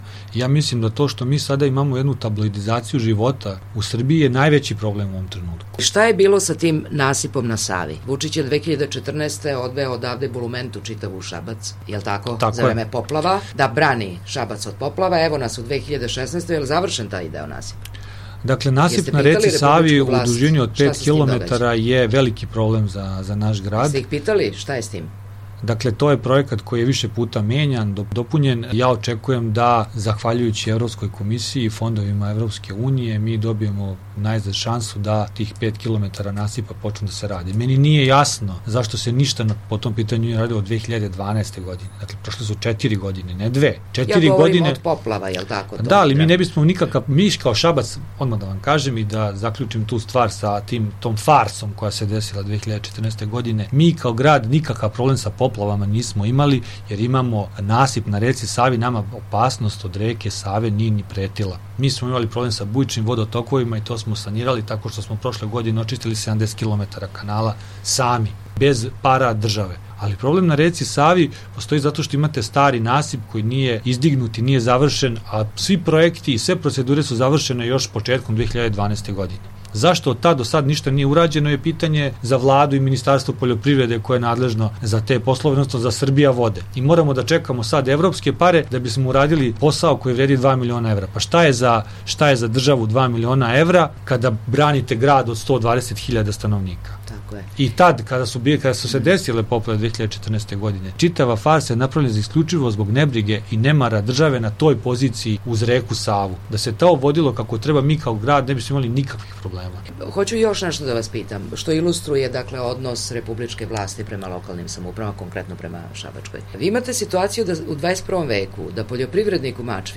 Intervju nedelje: Nebojša Zelenović